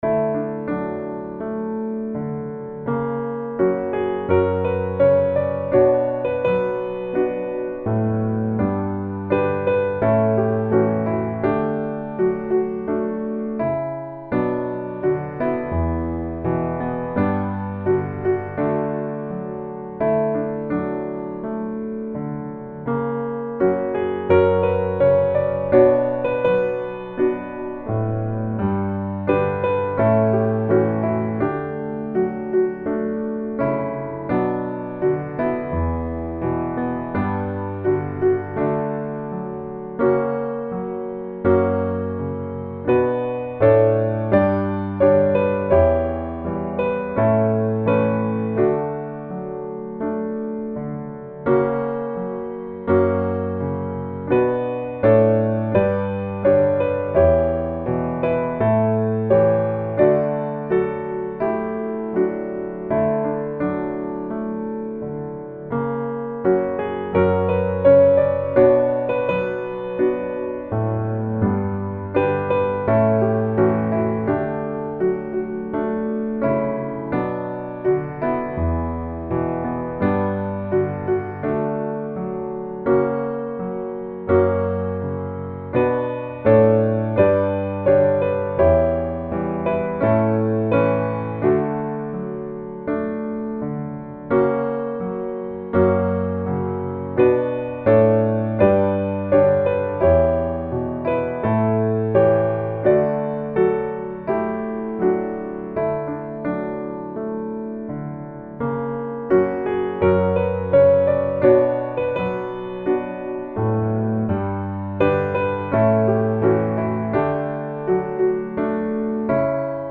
Gospel
D Major